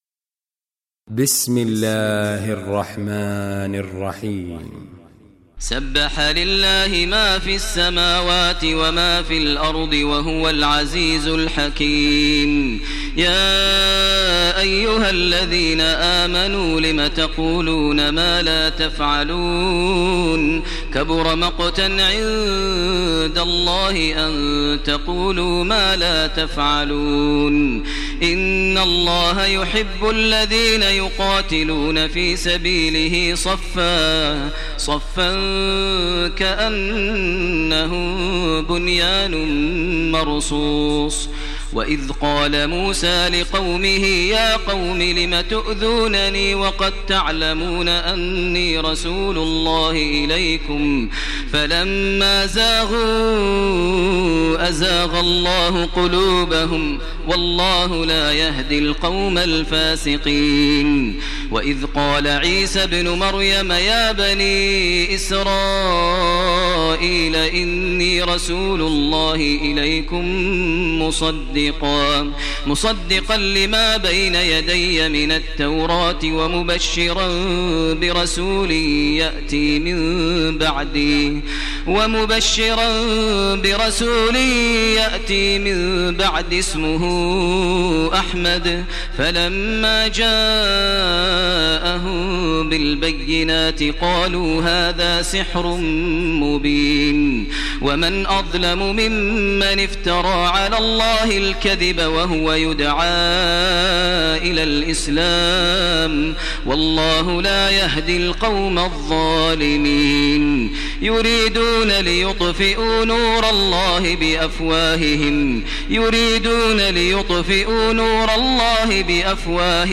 Surah Saff Recitation by Sheikh Maher al Mueaqly
Surah Saff, listen online mp3 tilawat / recitation in Arabic in the voice of Imam e Kaaba Sheikh Maher al Mueaqly.